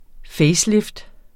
Udtale [ ˈfεjsˌlefd ]